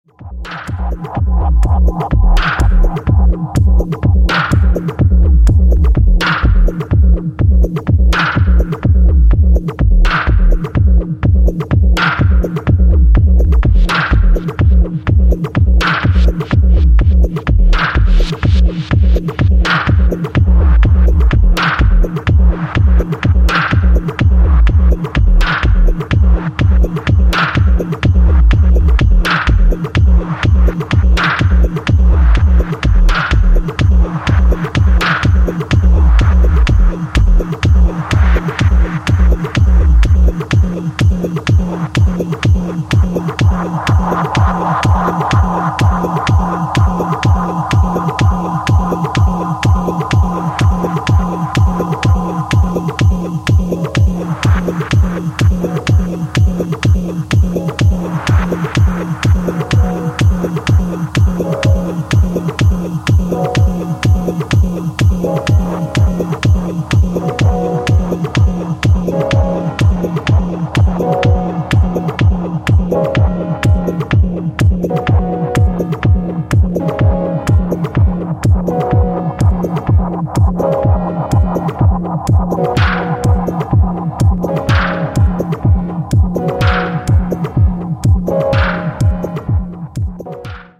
is an entrancing, thumping, club focused 12''.
House Techno Acid